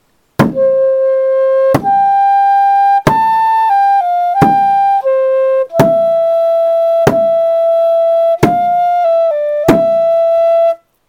Thor Chant Mantras and Yantras Chant Melody audio (no words) Thor, Thor, Hardhughadr, Thor, Thor, Asa-Thor.
thor_chant.mp3